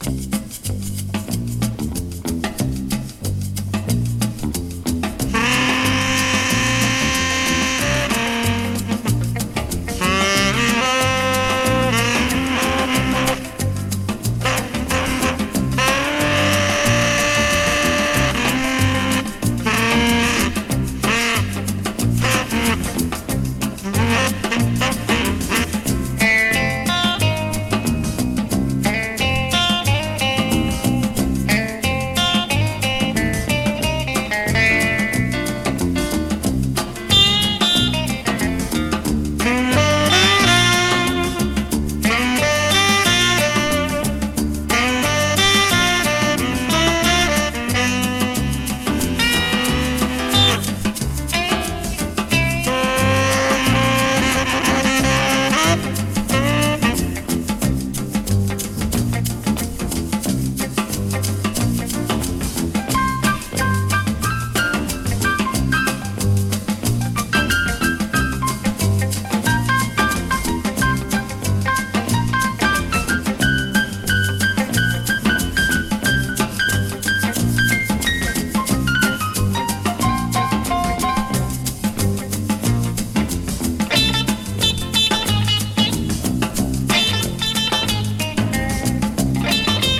JAZZ FUNK / RARE GROOVE / JAPANESE GROOVE
ドープでスリリングな和モノ・ジャズ・ファンク/レア・グルーヴとして推薦！